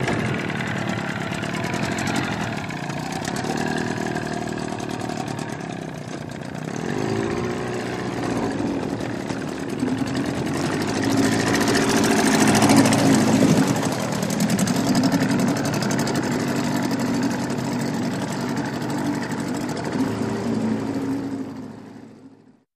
20 hp Johnson Boat Away Drive Away From Dock